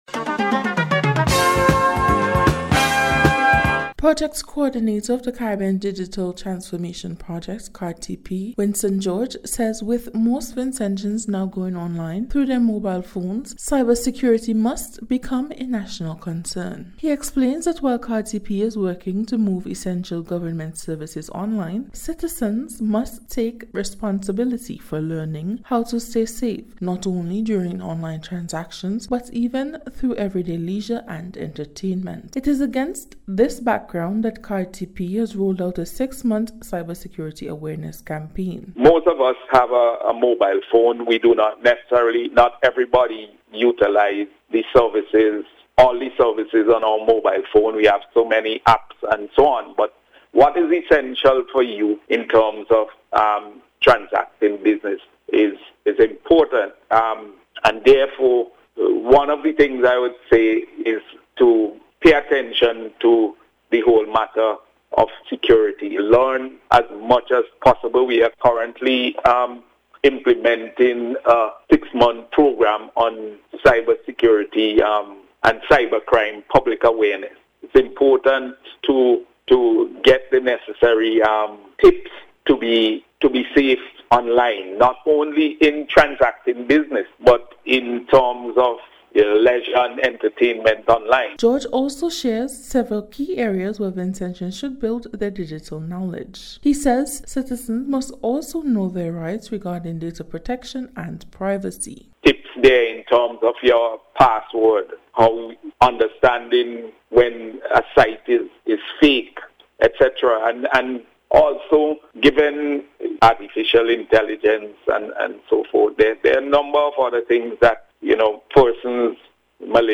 NBC’s Special Report- Monday 1st November,2025
CARDTP-CYBERSECURITY-REPORT.mp3